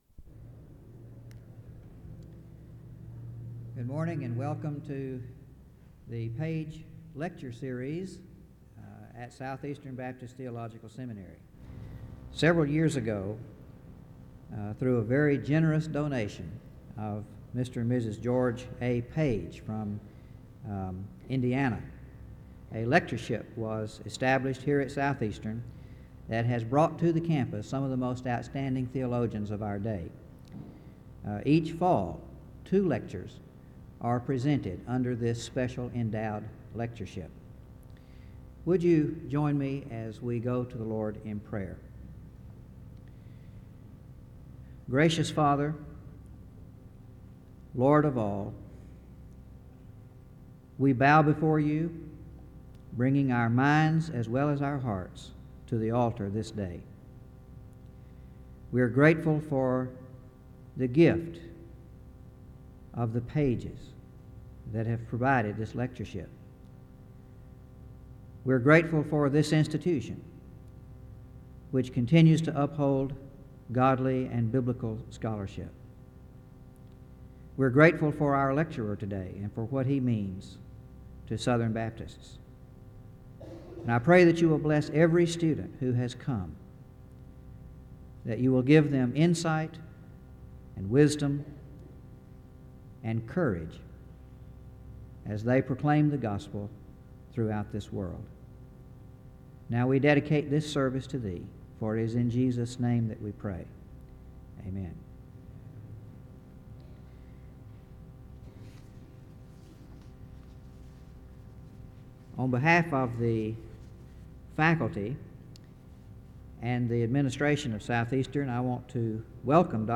SEBTS Page Lecture
SEBTS Chapel and Special Event Recordings